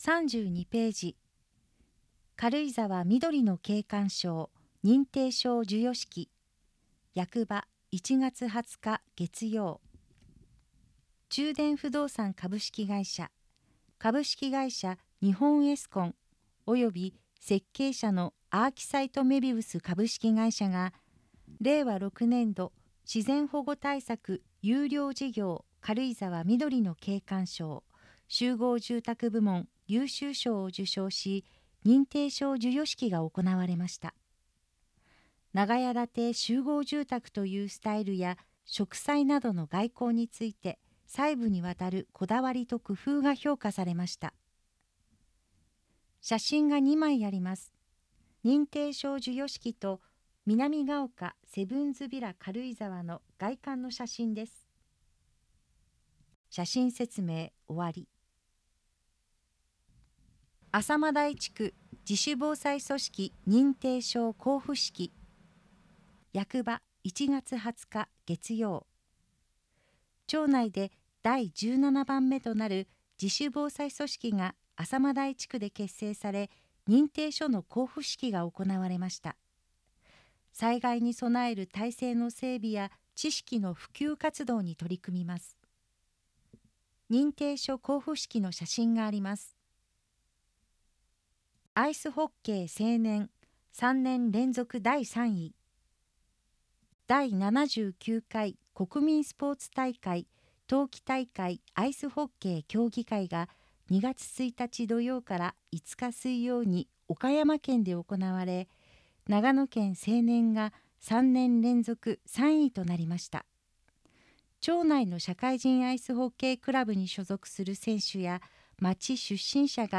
音声データ　軽井沢図書館朗読ボランティア「オオルリ」による朗読